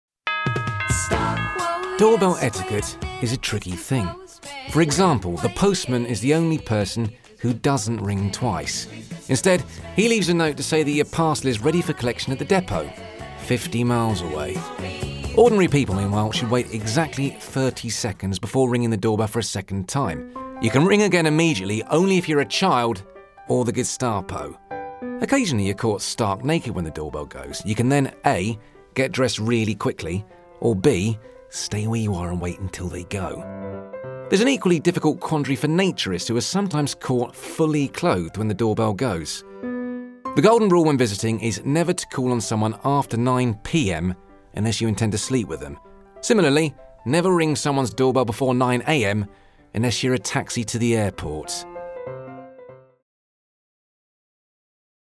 Warm, voll und ohne regionalen Akzent. Er arbeitet von seinem Heimstudio aus und verwendet nur die beste Ausrüstung.
Ich verwende ein Sennheiser MKH-416-Mikrofon, Audacity, Focusrite Scarlett Solo und habe ein komplett ausgestattetes Aufnahmestudio.